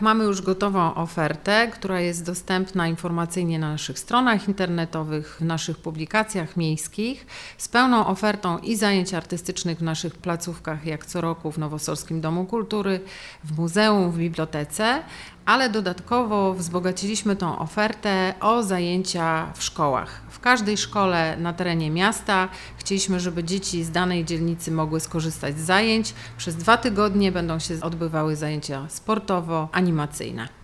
– W wielu miejskich placówkach będą odbywały się zajęcia dla dzieci i młodzieży – powiedziała Natalia Walewska – Wojciechowska, wiceprezydent miasta: